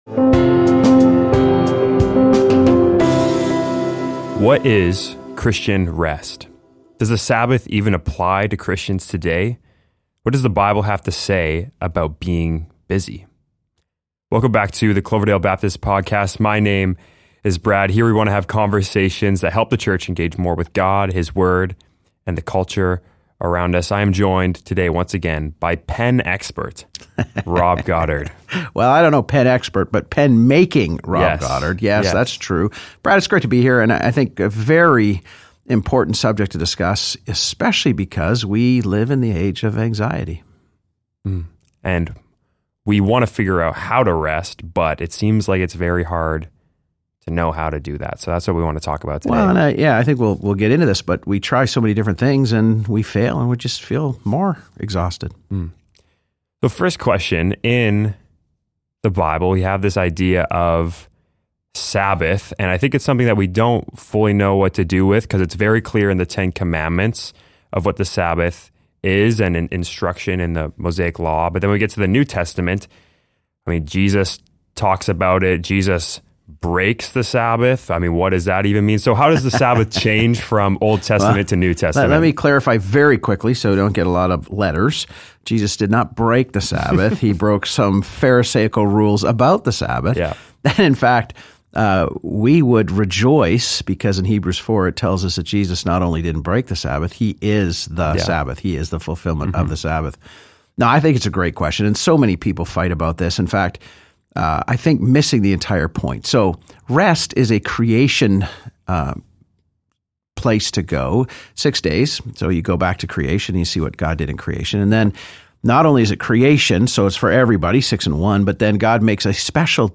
Tune in for a thoughtful conversation on how to embrace and obey the sacred rhythm of rest in today's hectic world.